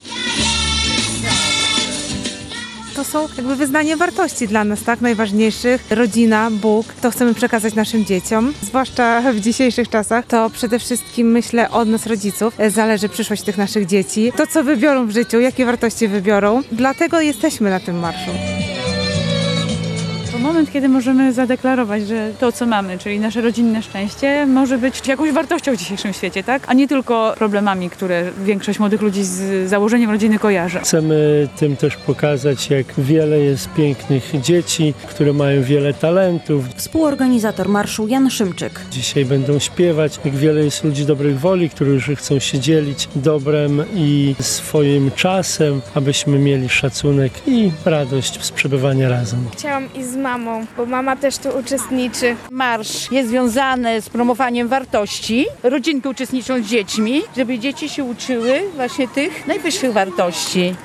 Radio Białystok | Wiadomości | Wiadomości - Ulicami Suwałk przeszedł Marsz dla Życia i Rodziny